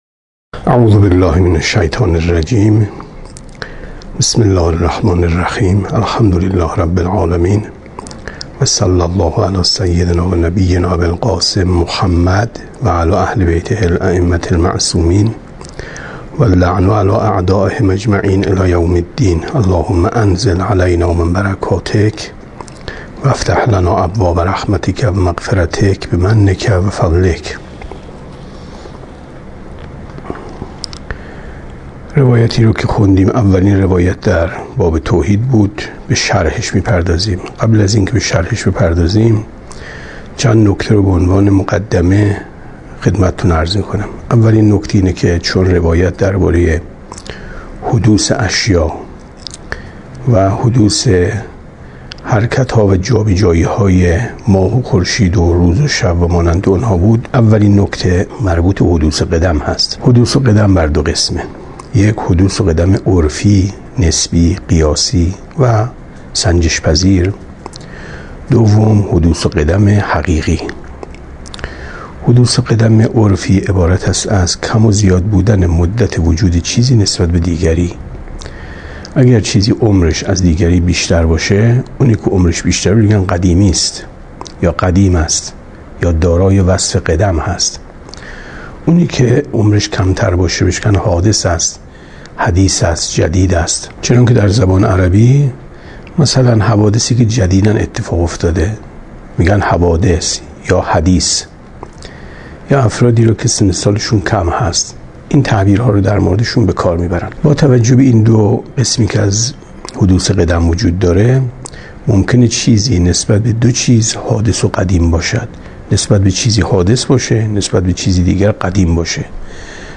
کتاب توحید ـ درس 6 ـ 6/ 5/ 95 شرح اصول کافی درس 124 بدترین ناسزایی که به خدای متعال می‌توان گفت!